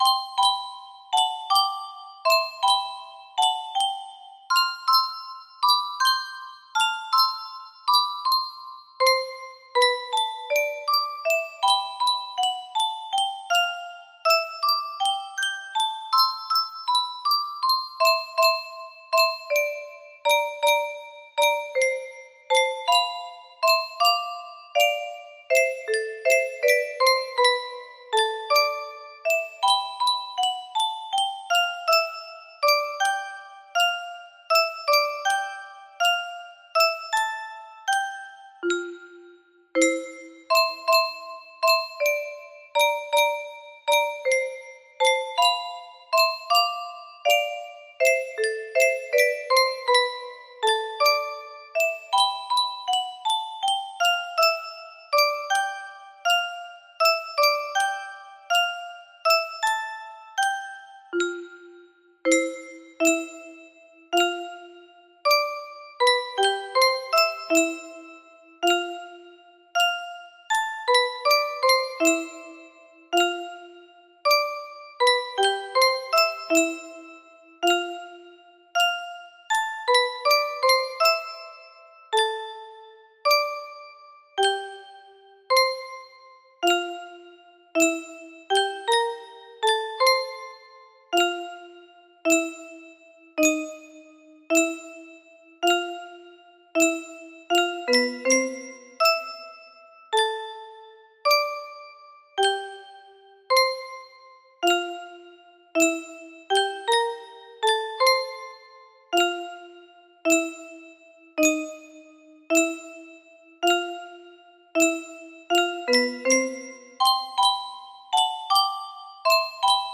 Ludwig van Beethoven (1770–1827) - Sonate No. 14, Moonlight P2 music box melody